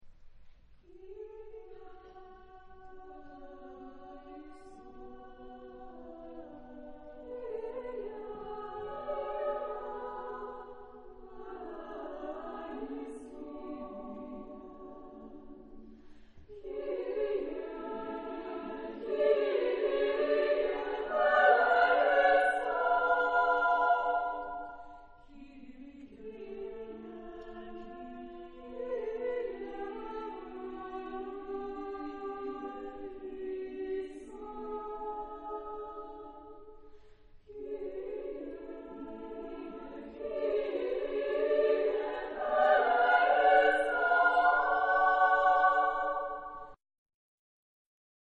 Genre-Style-Form: Sacred ; Contemporary ; Mass
Mood of the piece: cantabile ; lively ; prayerful ; calm
Type of Choir: SSAA  (4 children OR women voices )
Tonality: A major ; D major ; free tonality